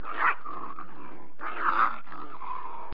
1 channel
hundklei.mp3